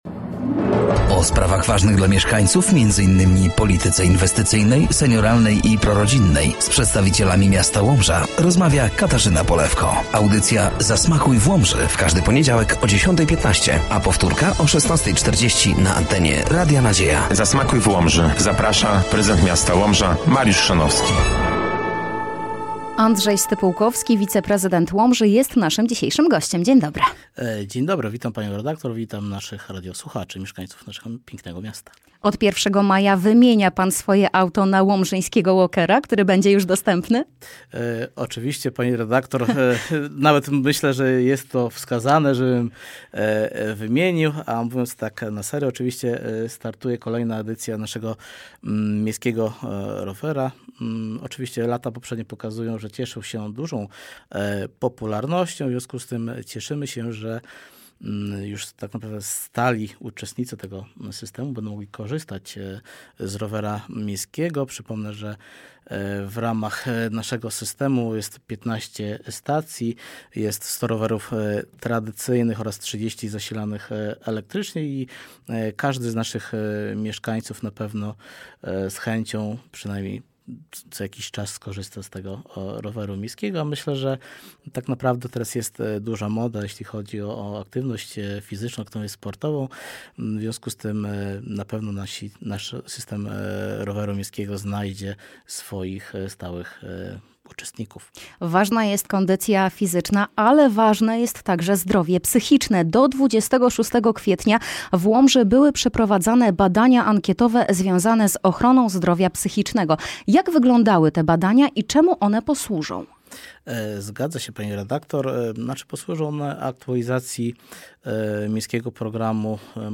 Gościem dwunastej audycji był Andrzej Stypułkowski, wiceprezydent Łomży.